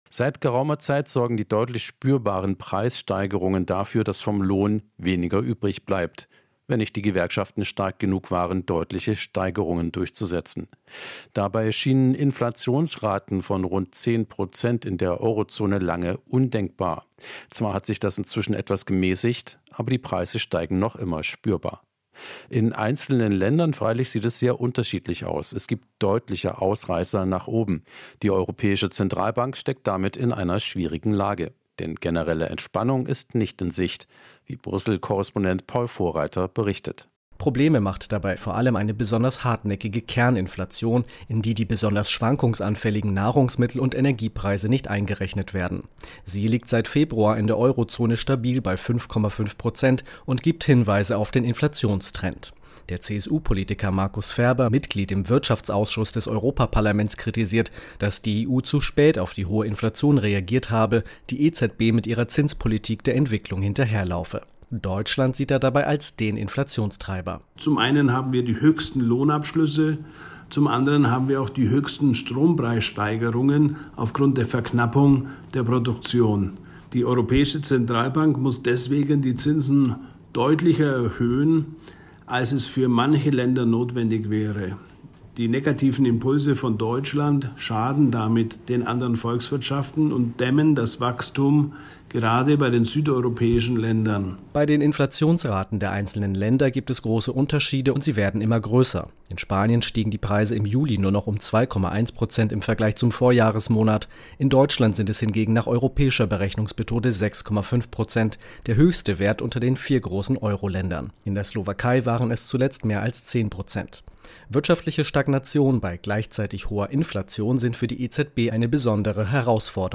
Inforadio Nachrichten, 12.08.2023, 08:40 Uhr - 12.08.2023